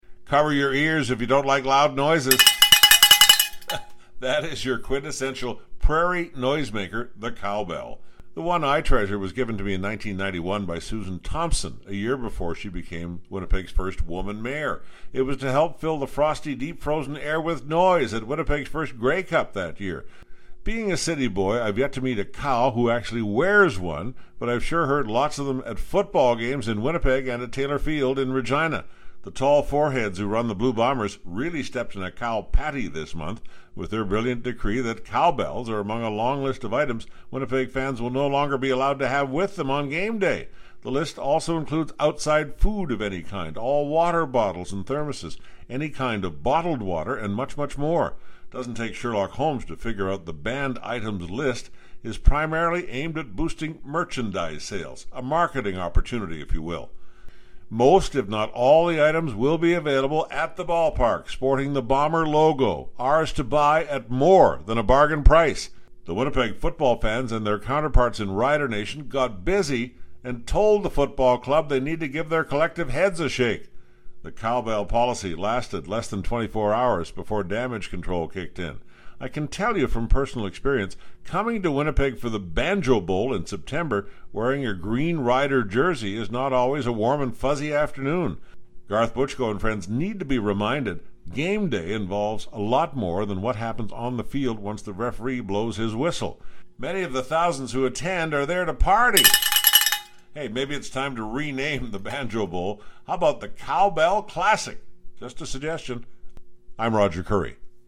Quintessential prairie noisemaker, the cowbell.